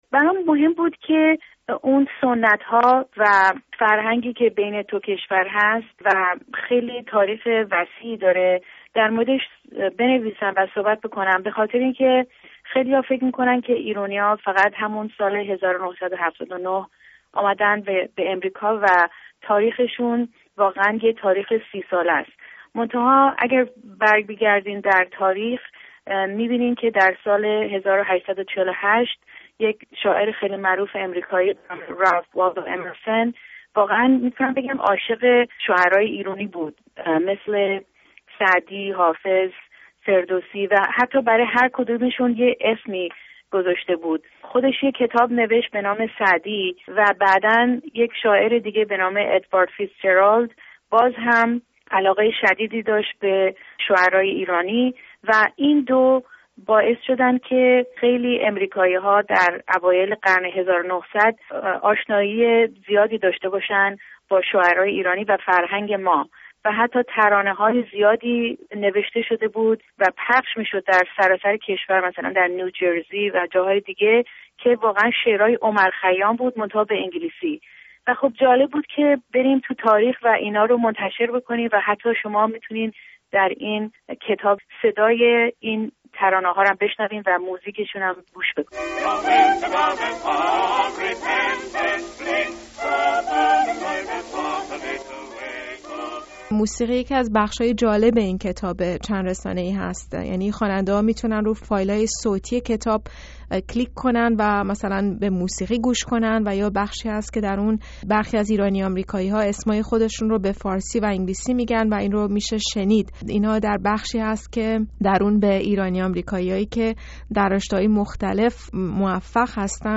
نشانگان ایرانی در آمریکا؛ گفت‌وگو